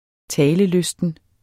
Udtale [ -ˌløsdən ]